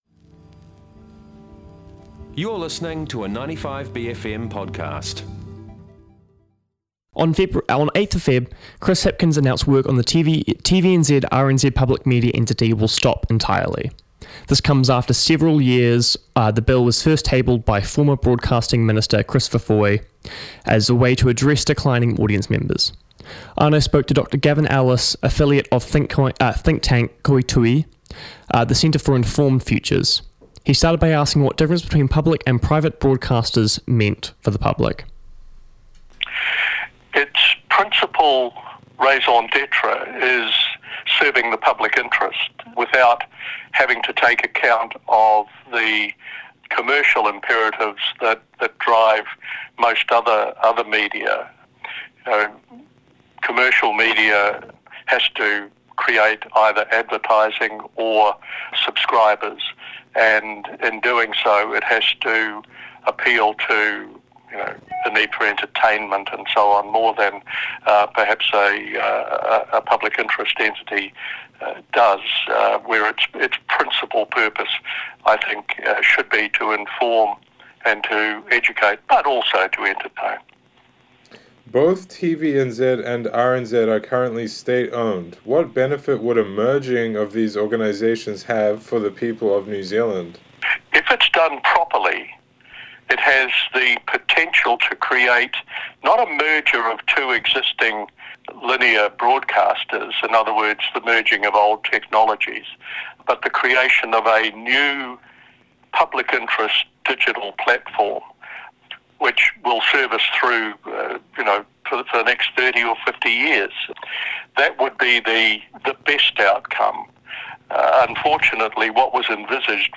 News & Current Affairs show